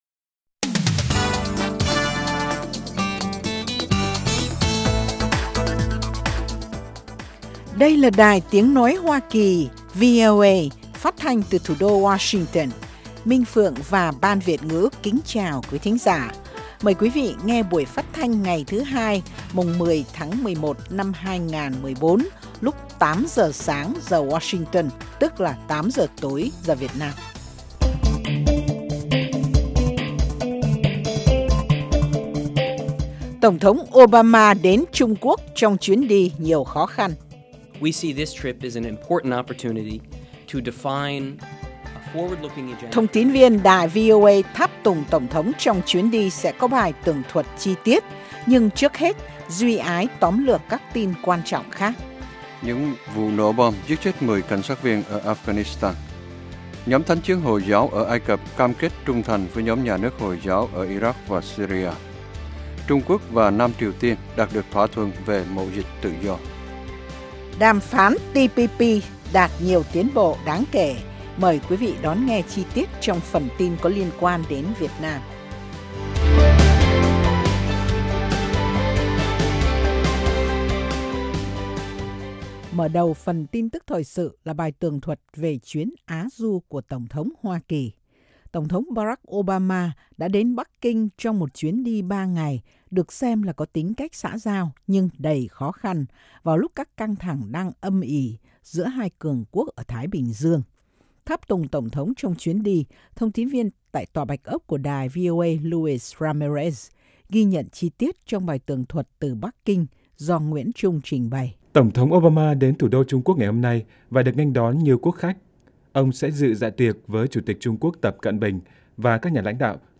Tin tức mới nhất và các chuyên mục đặc biệt về Việt Nam và Thế giới. Các bài phỏng vấn, tường trình của các phóng viên ban Việt ngữ về các vấn đề liên quan đến Việt Nam và quốc tế.